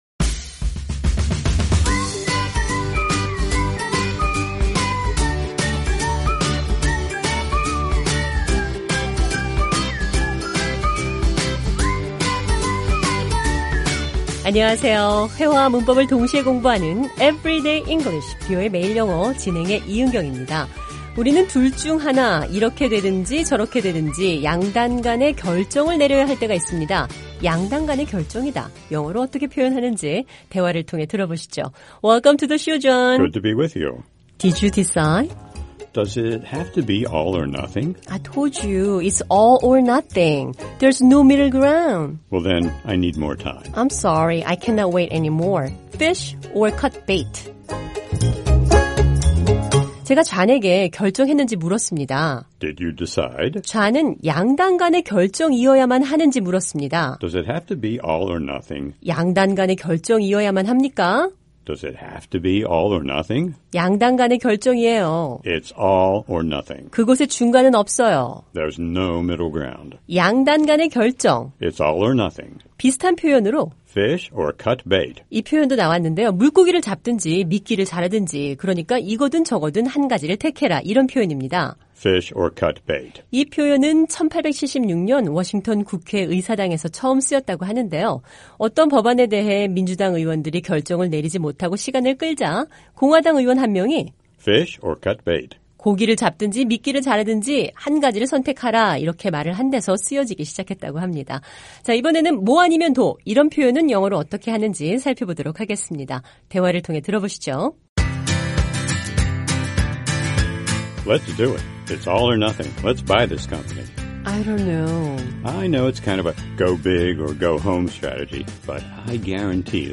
영어로 어떻게 표현하는지 대화를 통해 들어보시죠.